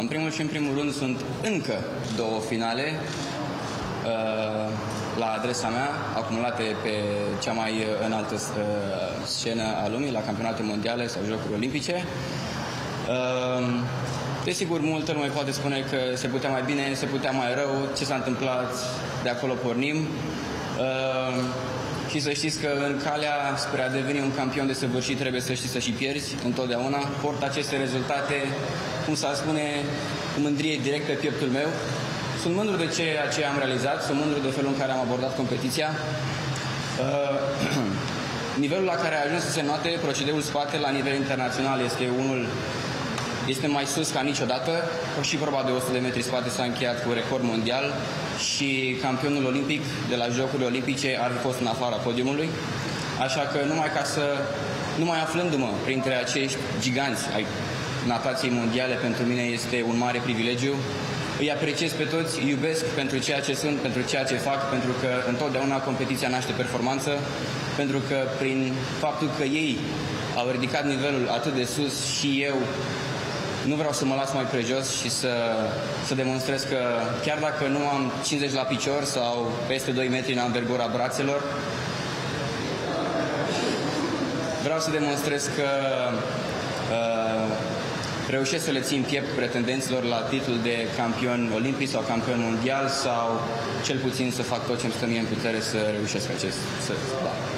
Îţi mulţumesc ţie, că sunt contemporan cu tine, David…”, a spus Glinţă la ceremonia de la aeroportul Henri Coandă.